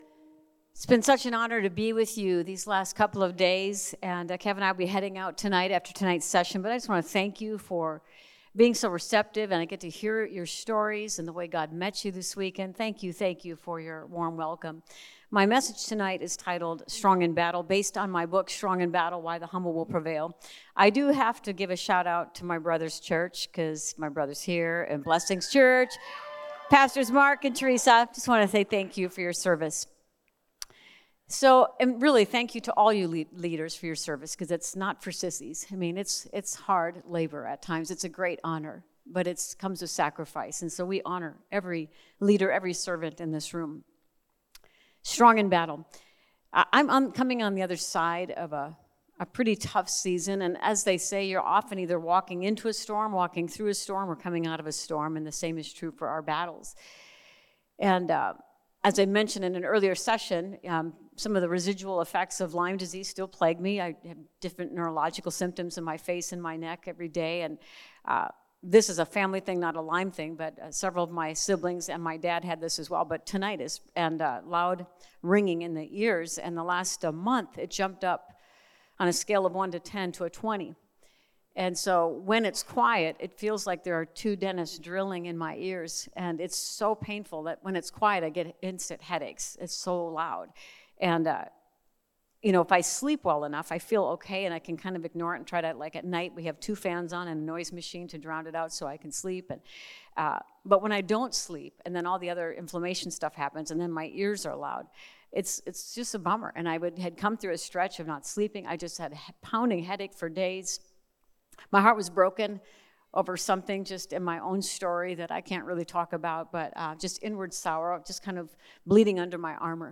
Message
at Family Camp, the evening of June 29